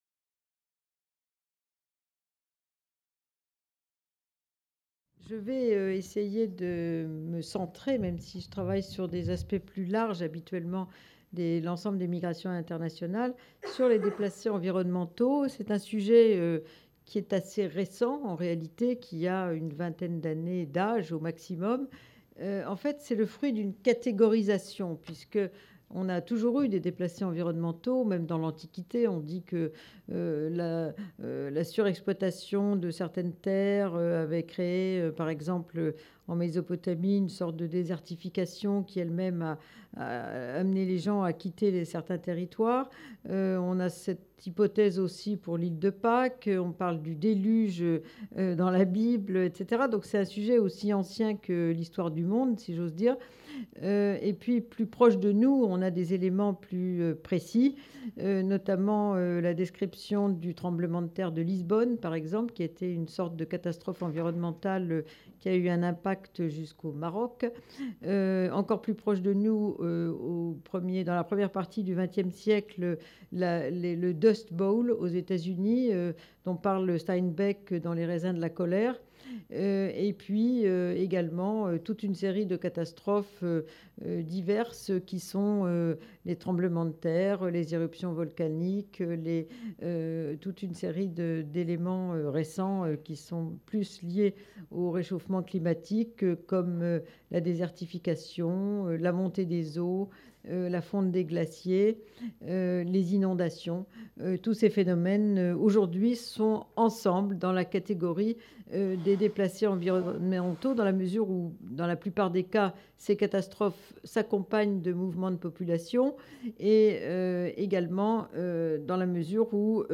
Cette communication a été prononcée dans le cadre du festival "Migrations et climat", qui s'est tenu à Caen du 18 au 20 avril 2018.